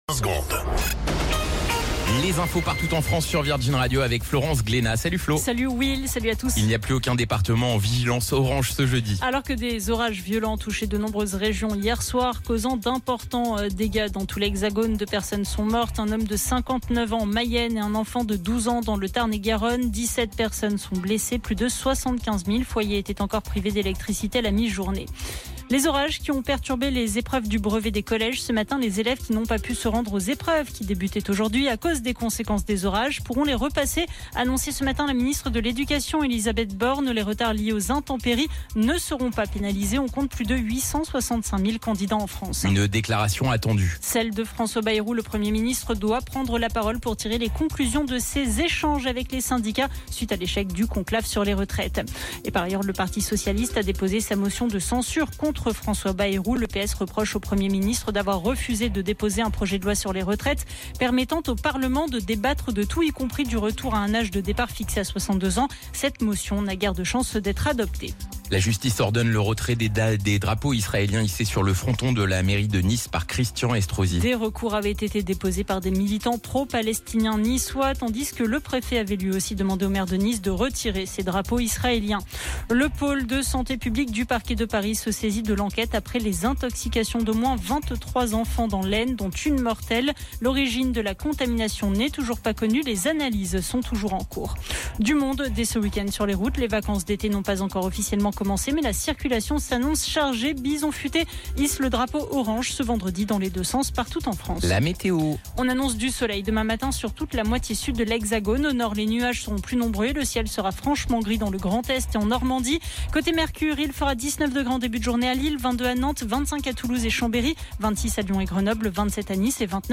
Flash Info National 26 Juin 2025 Du 26/06/2025 à 17h10 .